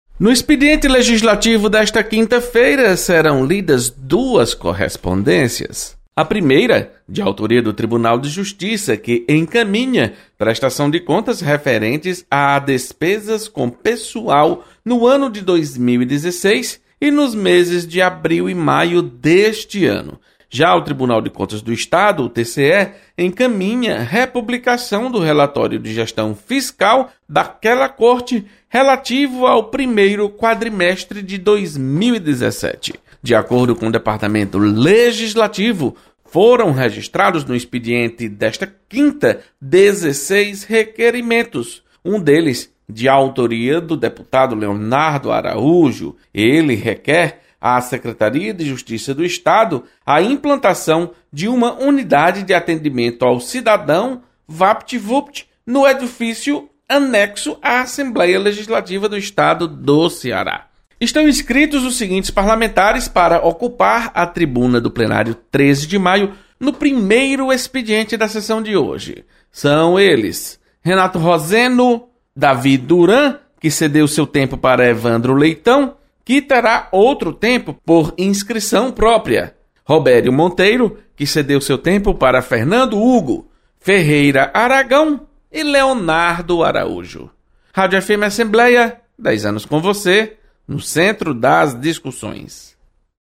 Acompanhe as informações do expediente legislativo desta quinta-feira com o repórter